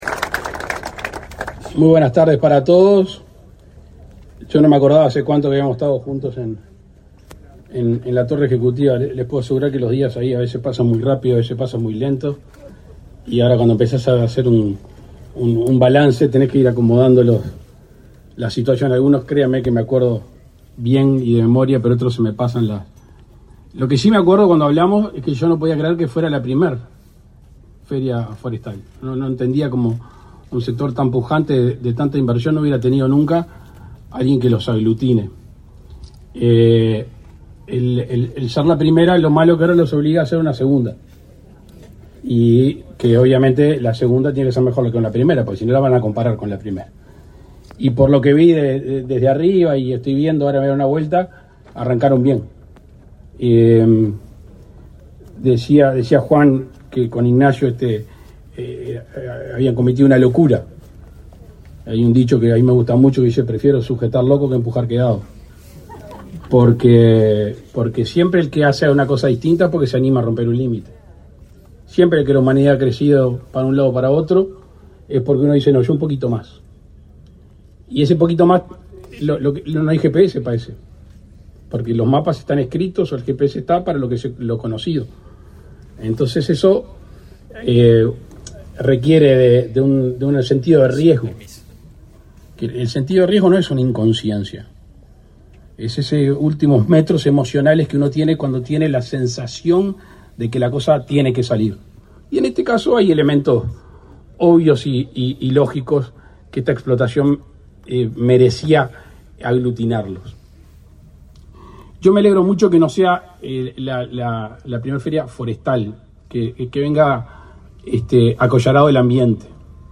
Palabras del presidente de la República, Luis Lacalle Pou
El presidente de la República, Luis Lacalle Pou, participó, este 10 de octubre, en la Feria Forestal, del Agro y el Ambiente, en Juan Lacaze, Colonia.